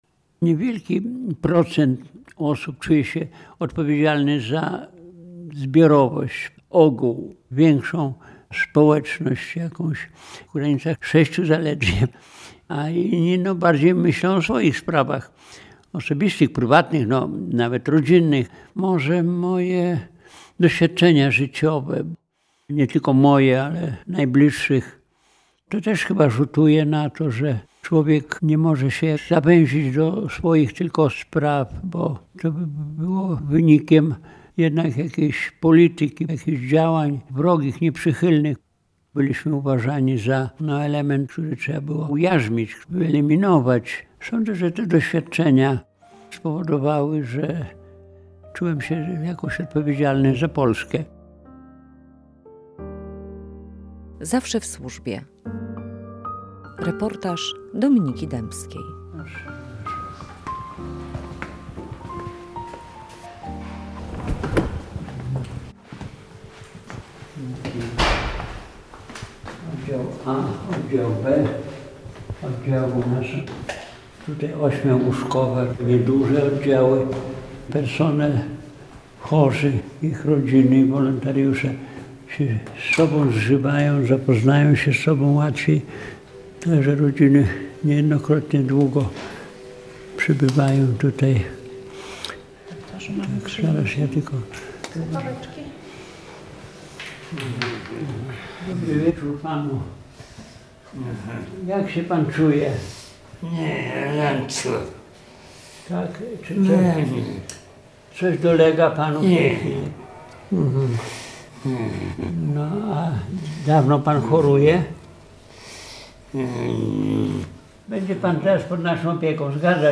Radio Białystok | Reportaż